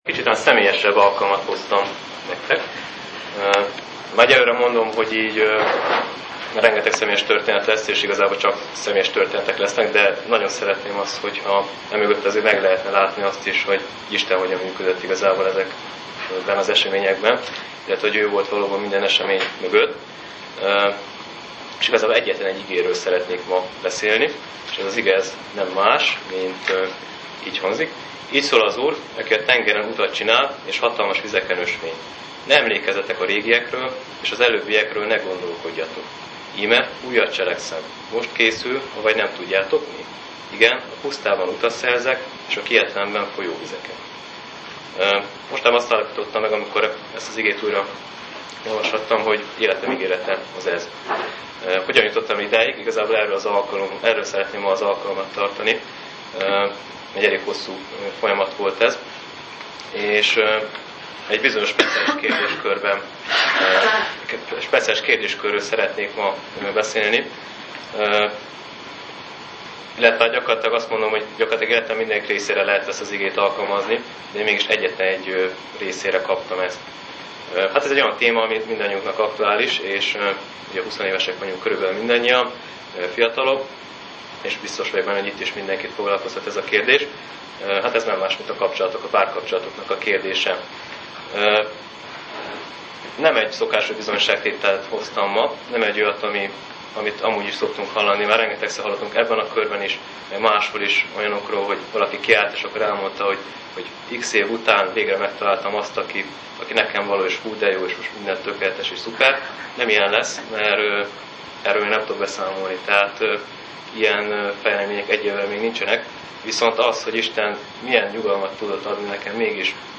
Bizonyságtétel
Az alkalom a novemberi ifihétvégén készült, krónika nem iratott.